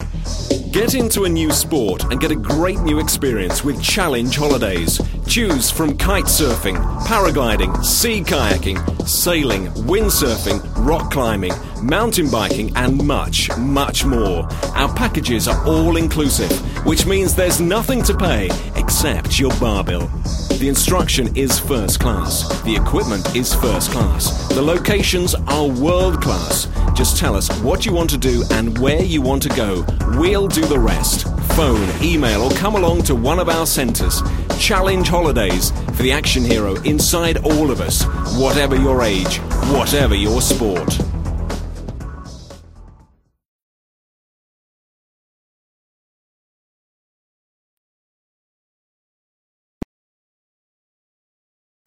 With twenty years of experience working in radio for the BBC and a rich, warm, cultured voice
Sport Ad
05-Sport-Ad_.mp3